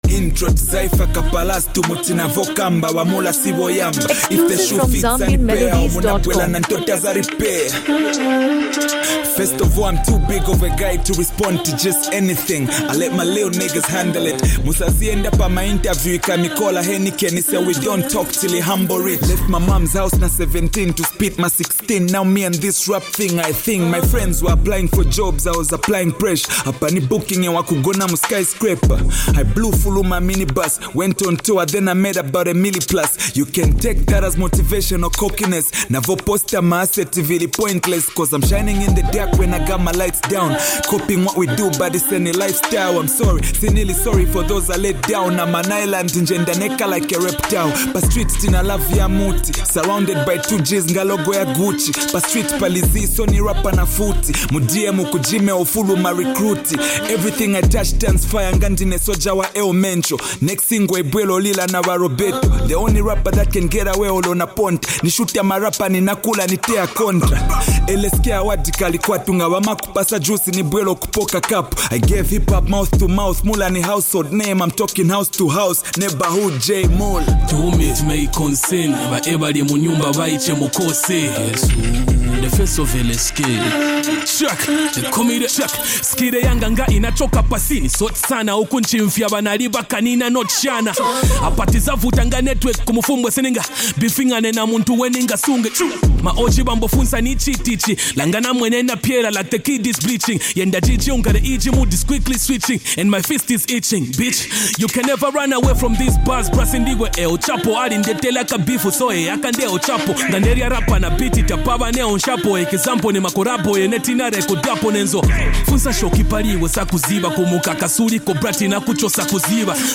Zambian Hip Hop
Genre: Hip Hop